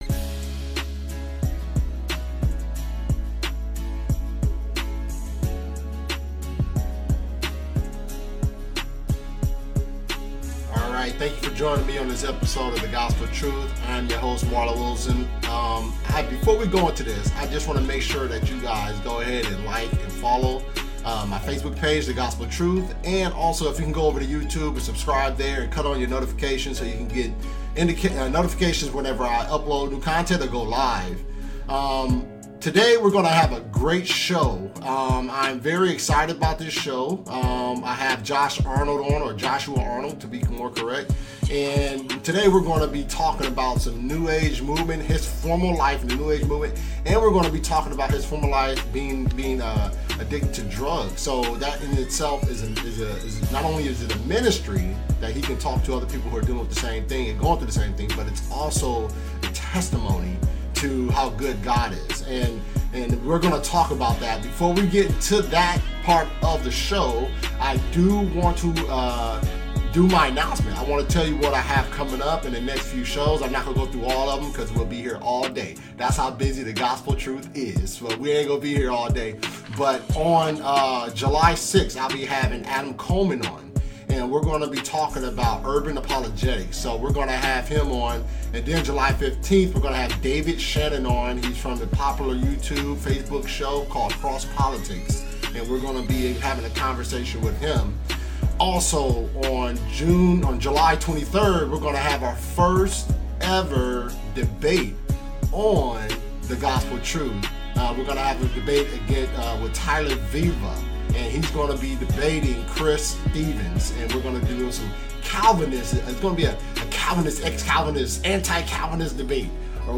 Interview: Testimony and New Age Movement